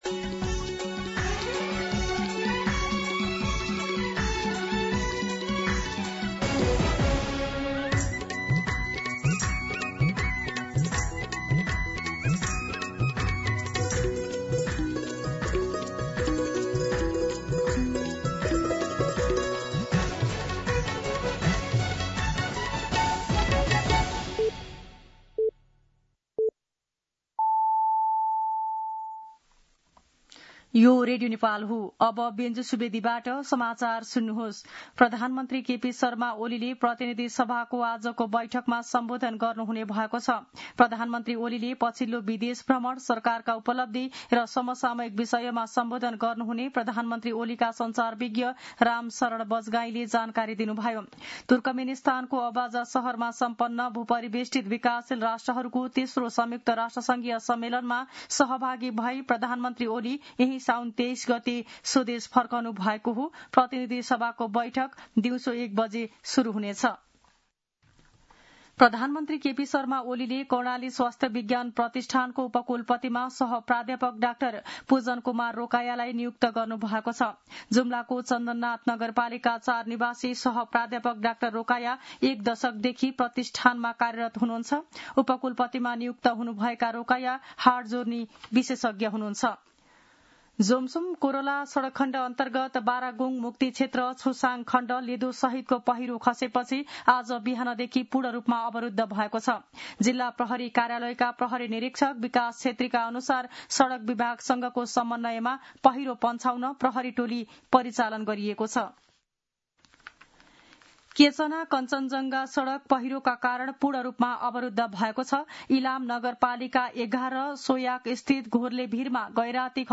मध्यान्ह १२ बजेको नेपाली समाचार : २८ साउन , २०८२